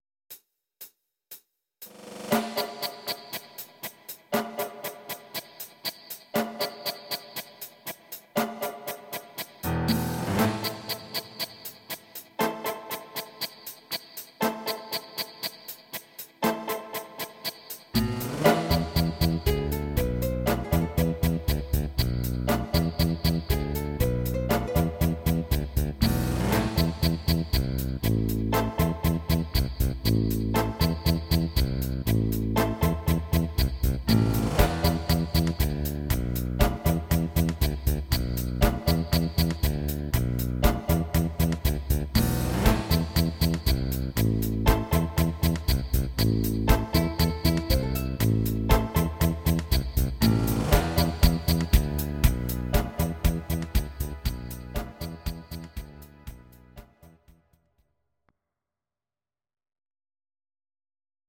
These are MP3 versions of our MIDI file catalogue.
Please note: no vocals and no karaoke included.
tango